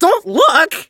chester_ulti_vo_03.ogg